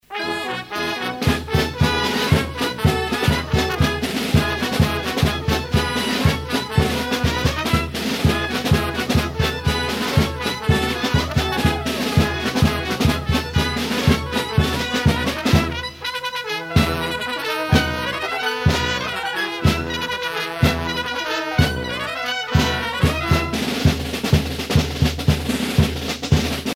circonstance : carnaval, mardi-gras
Pièce musicale éditée